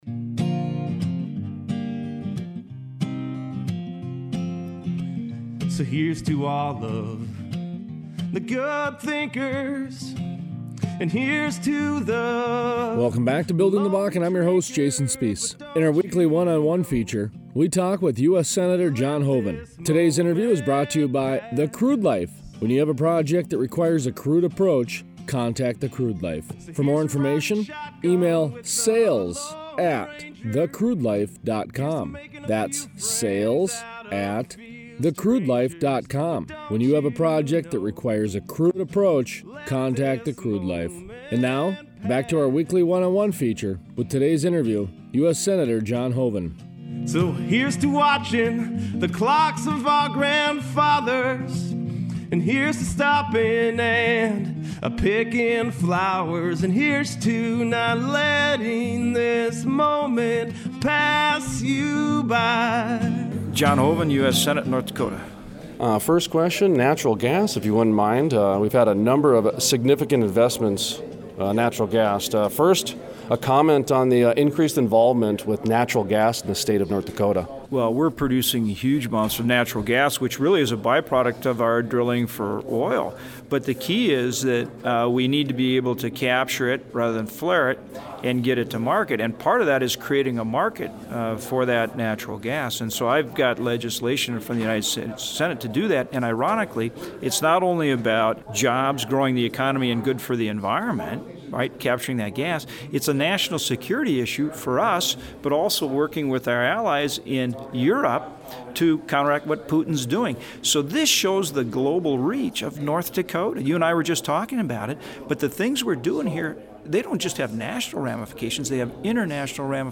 Interviews: US Senator John Hoeven